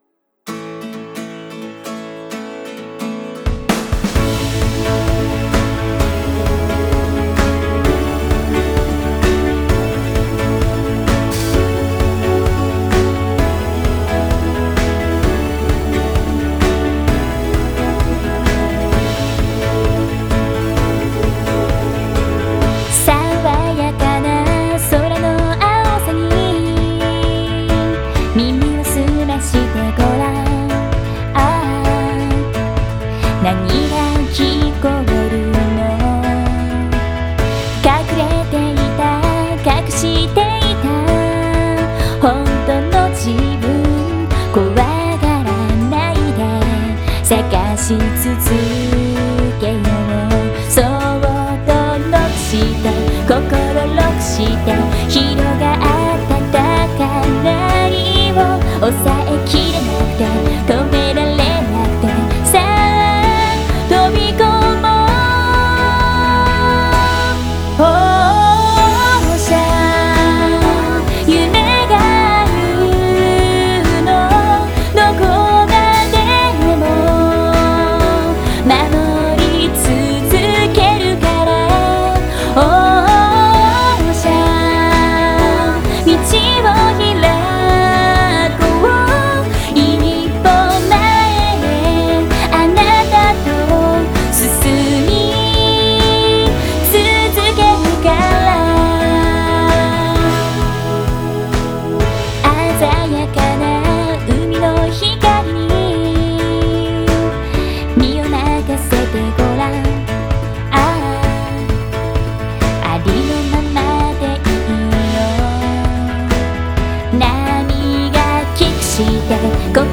IMAGE SONG